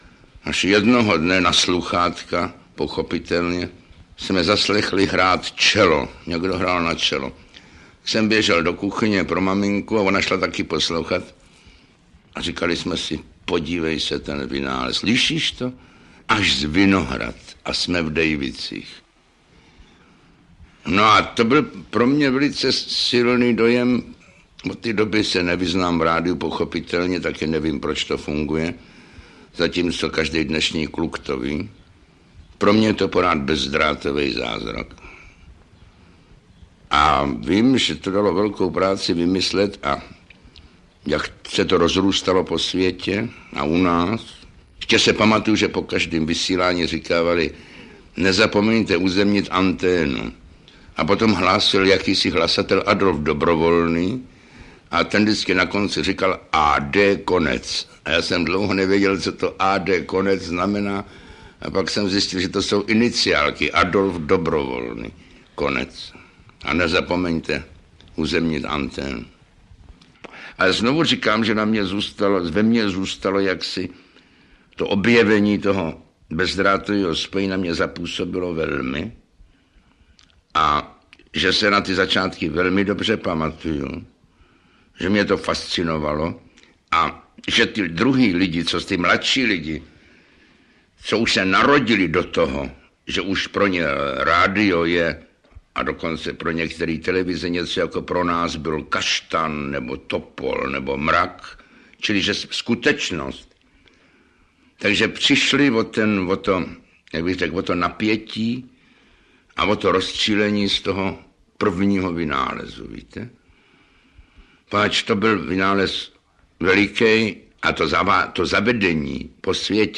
Babí léto Jana Wericha audiokniha
Audiokniha Babí léto Jana Wericha přináší premiéru několika neznámých zvukových dokumentů pro všechny příznivce Werichova vyprávění.
Ukázka z knihy